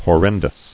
[hor·ren·dous]